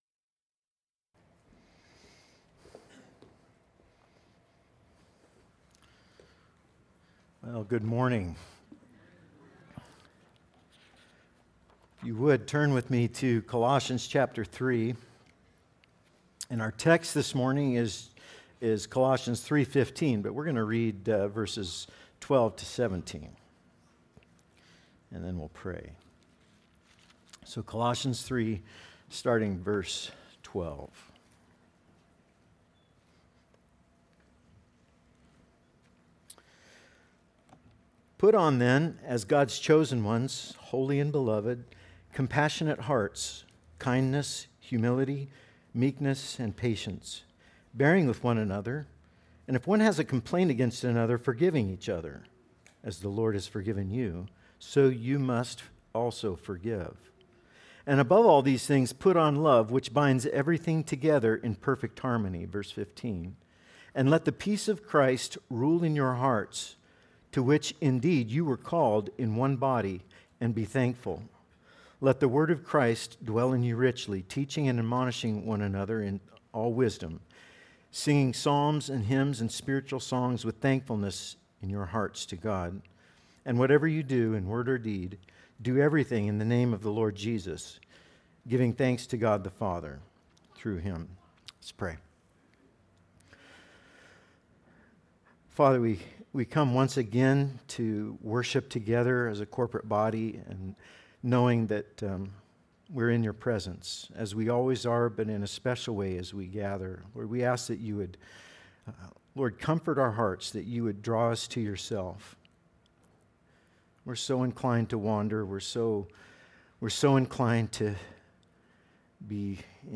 Our Sermons – Immanuel Baptist Church